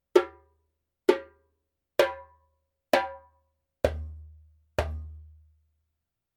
Djembe made in Mali
レンケ胴にヤギの厚め皮。皮は張ってから年数を経ていますが、そう叩き込まれていなく、まだまだ明るい、いい音で使えます。
ジャンベ音